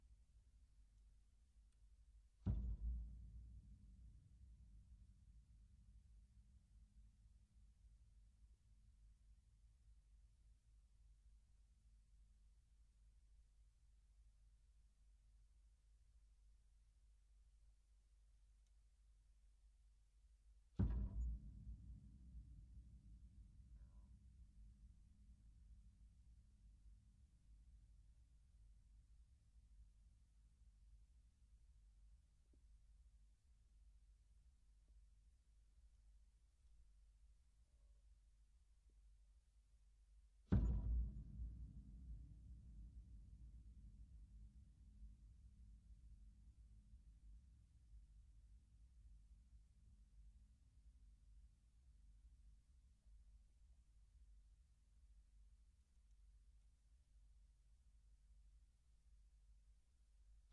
描述：立体声，24bit / 48kHz，Lewitt Authentica LCT 640
标签： 维持 钢琴 踏板 内饰
声道立体声